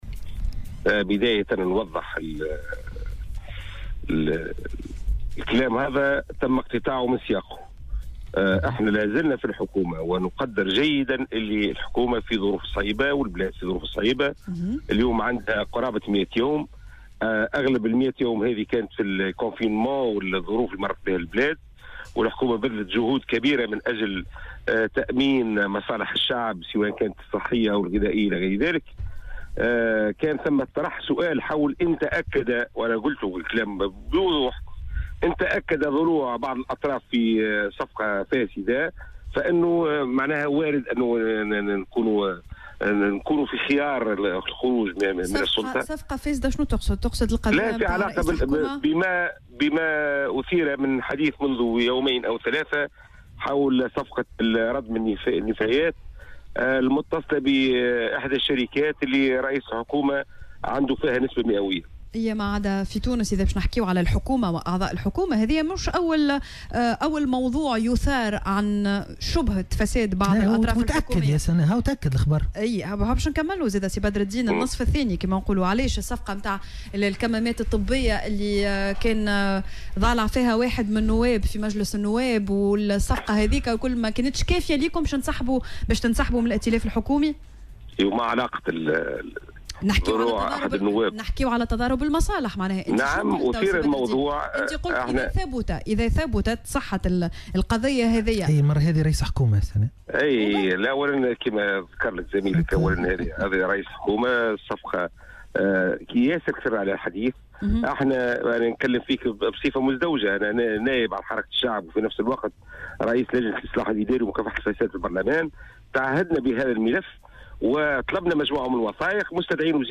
وأضاف في مداخلة له اليوم على "الجوهرة أف أم" : " مازلنا في الحكومة ونُقدر جيّدا أن البلاد تمر بوضع صعب والحكومة بذلت كل جهدها من أجل تأمين مصالح الشعب..وإن تأكد ضلوع بعض الأطراف في صفقة فاسدة، فإنه من الوارد أن ننسحب من الحكومة" (في إشارة إلى شبهة تضارب المصالح لدى رئيس الحكومة الياس الفخفاخ وامتلاكه اسهما في بعض الشركات التي تتعامل مع الدولة).